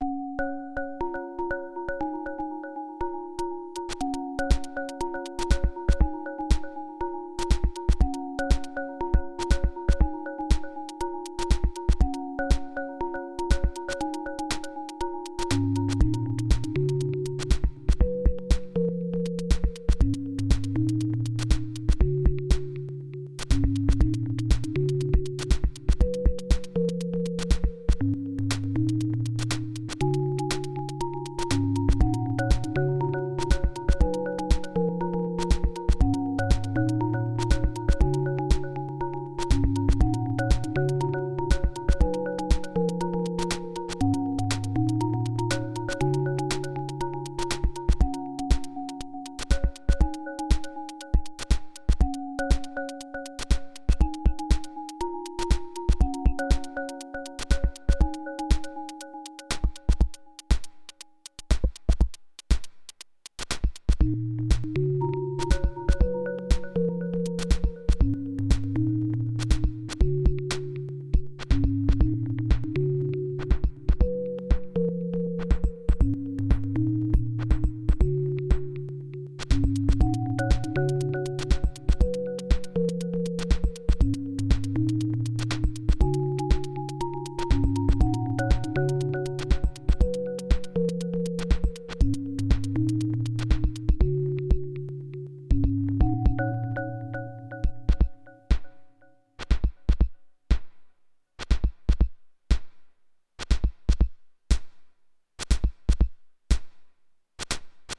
Recent rytm thing I made while experimenting with some Perf mode glitch effects.
Chords on from another synth but I could have easily sampled them in to make this 100% rytm.